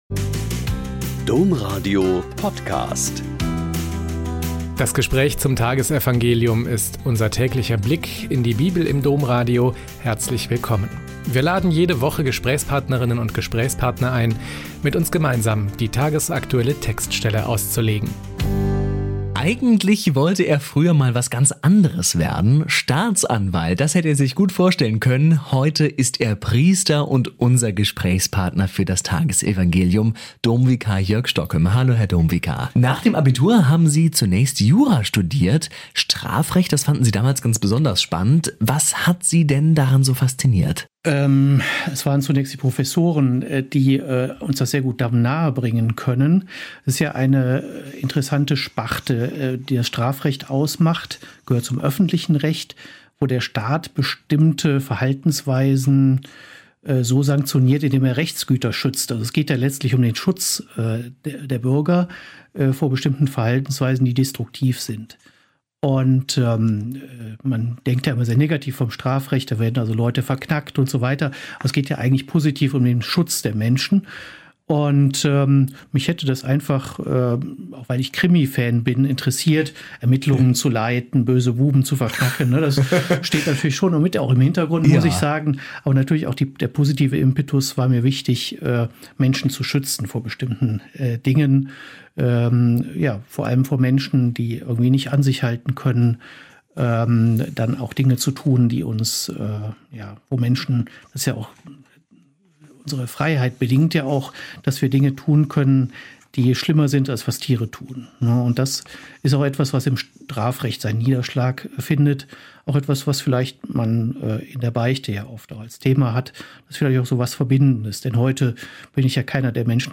Mt 13,36-43 - Gespräch